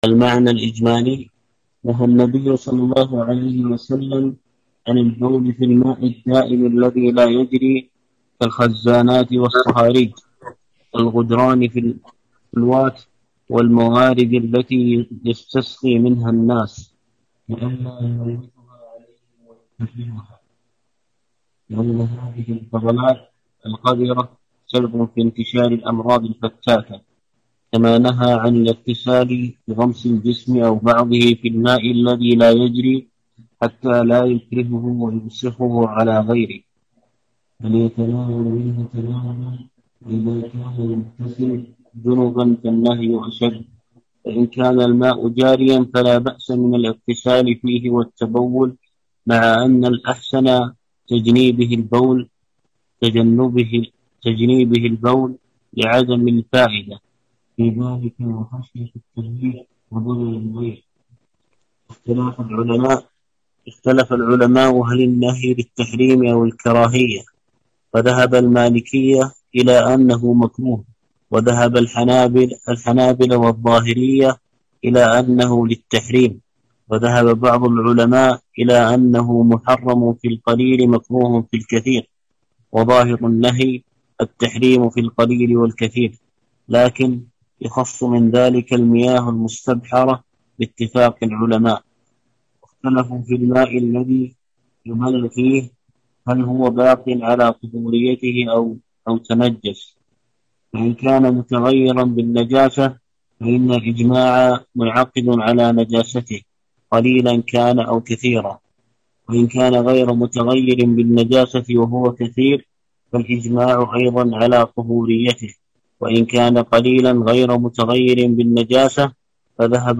2. الدرس الثاني شرح عمدة الأحكام